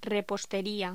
Locución: Repostería
voz
Sonidos: Voz humana